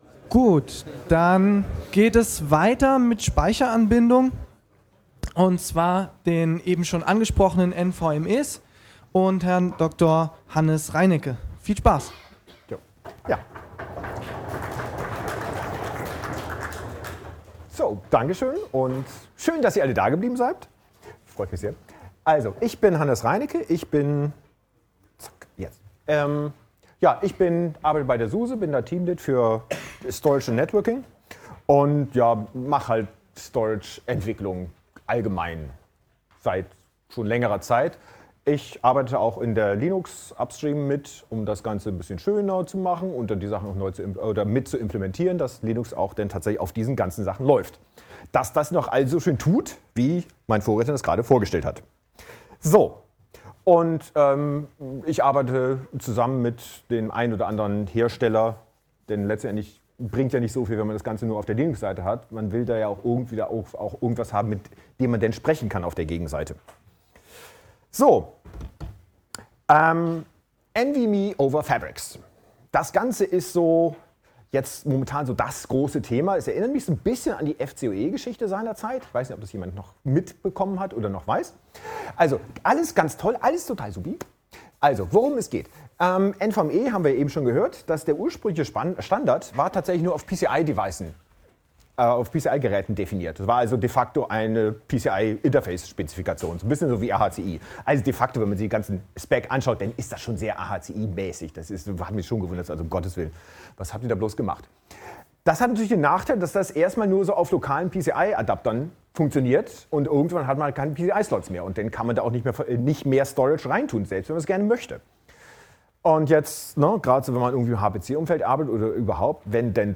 Chemnitzer Linux-Tage 2019 · Lecture: NVMe und NVMe-over-Fabrics